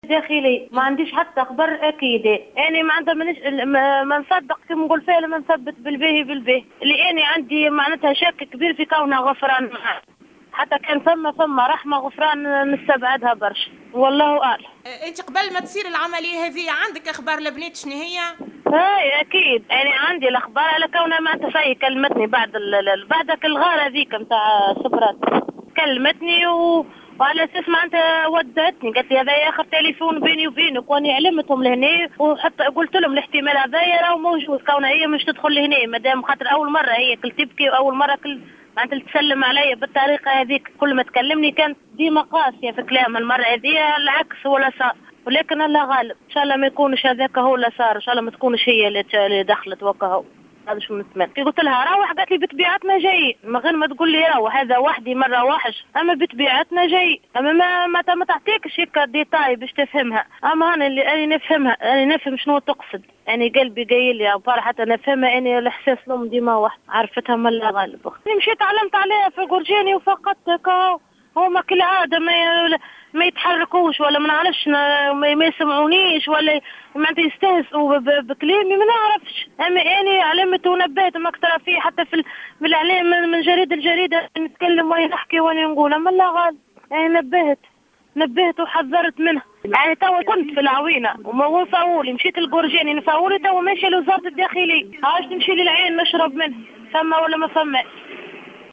في تصريح للجوهرة "اف ام"